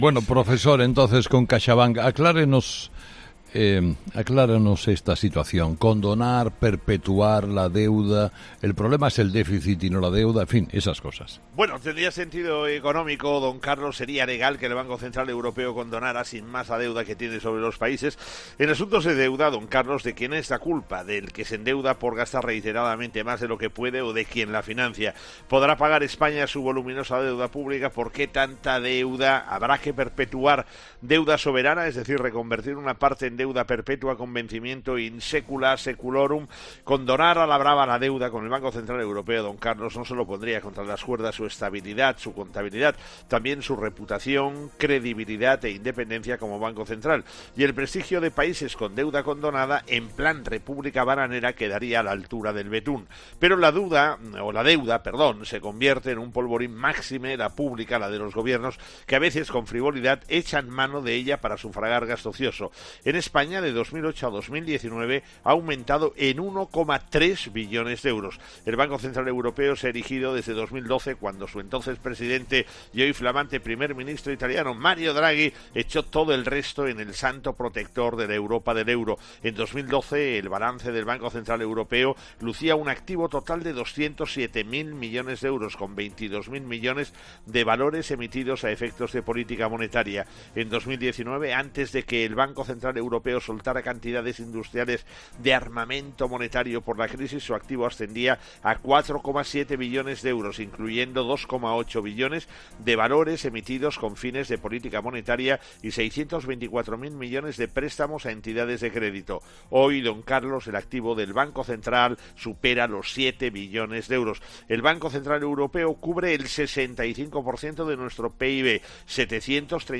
El profesor José María Gay de Liébana analiza en 'Herrera en COPE’ las claves económicas del día